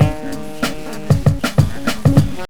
Music Loops